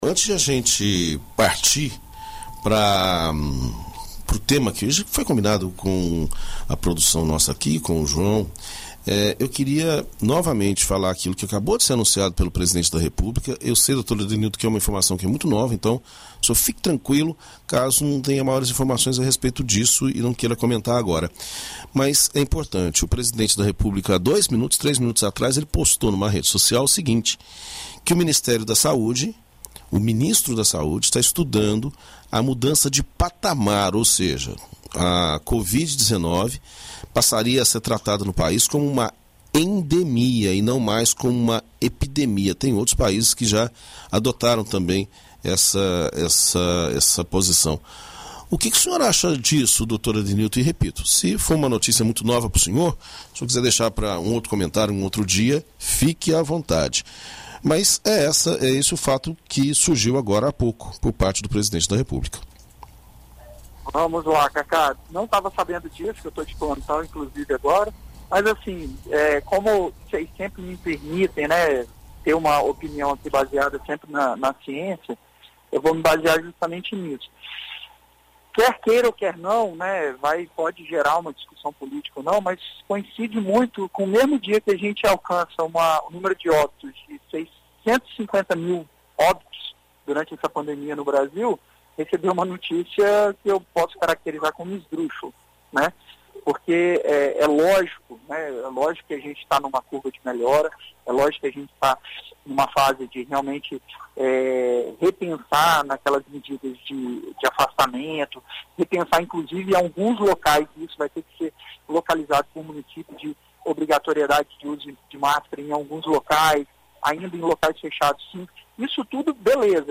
Visita Médica: médico explica causas e riscos da meningite